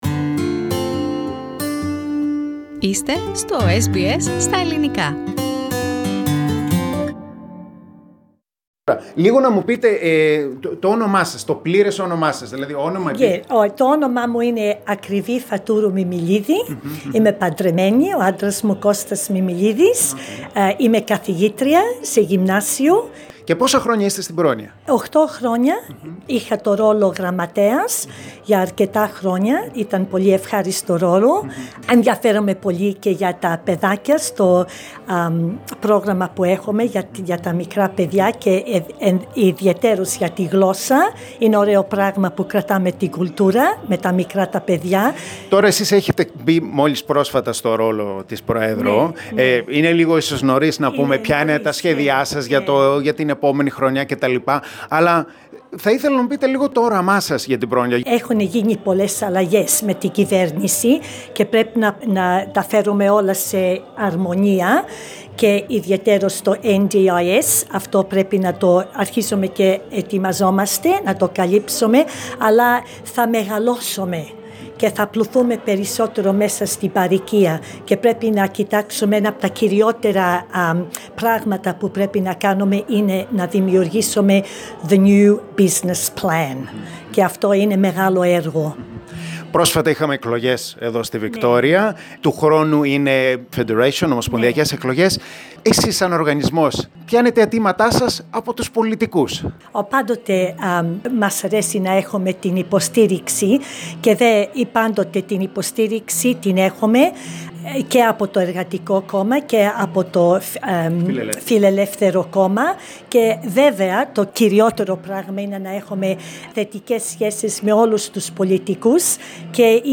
This article - interview is only in Greek.